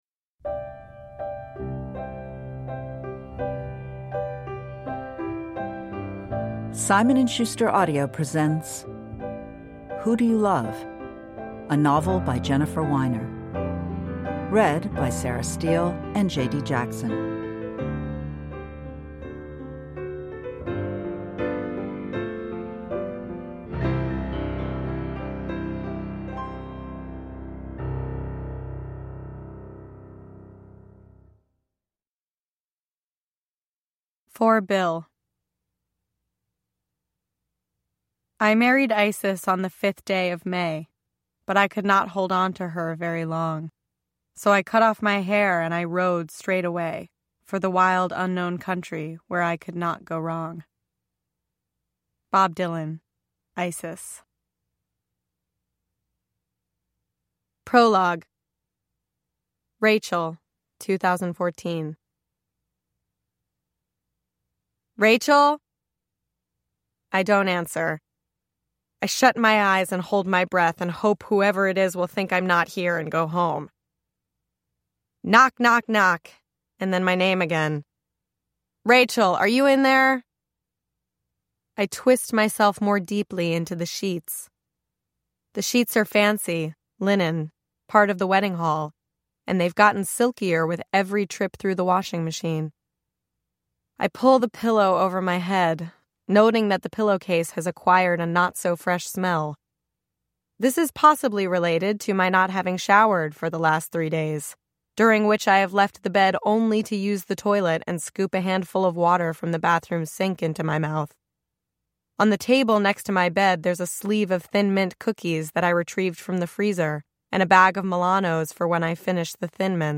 Who Do You Love – Ljudbok